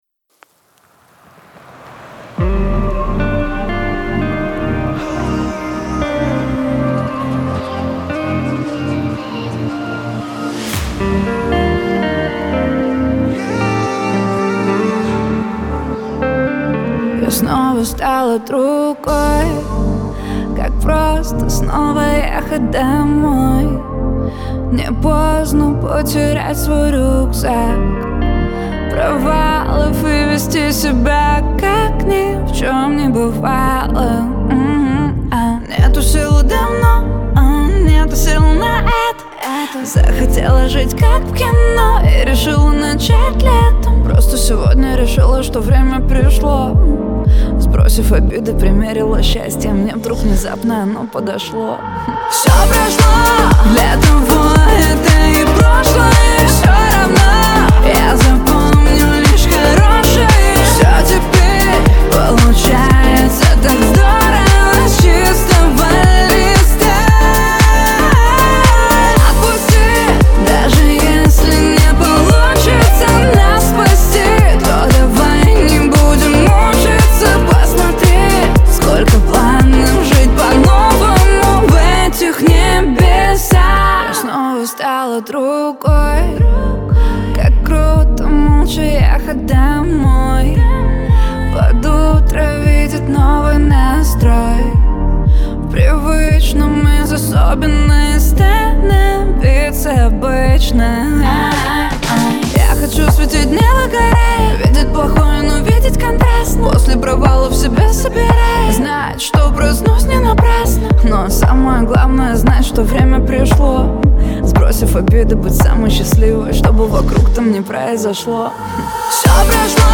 диско
pop
эстрада